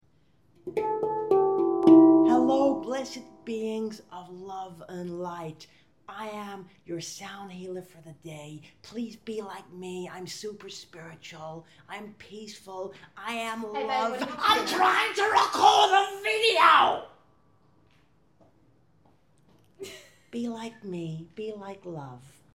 This skit is just messing around so please don’t take it too seriously.